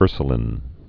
(ûrsə-lĭn, -līn, -lēn, ûrsyə-)